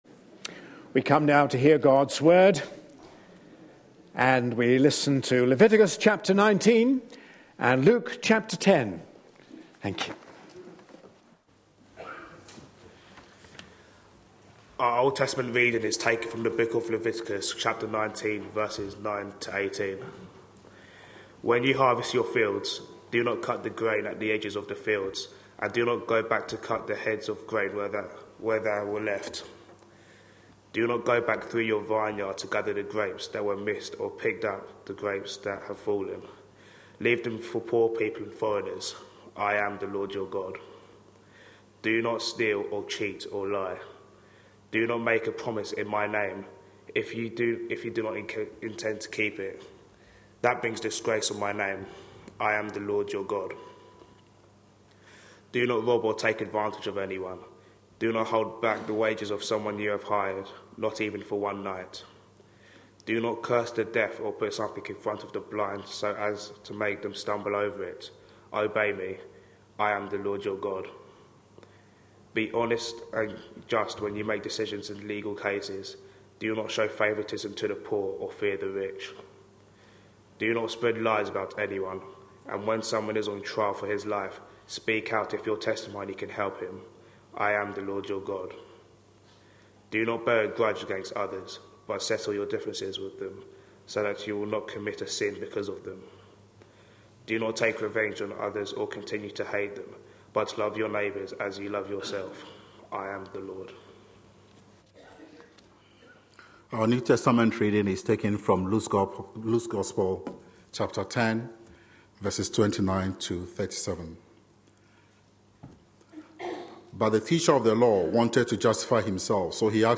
A sermon preached on 2nd February, 2014, as part of our Jesus -- His Challenge To Us Today. series.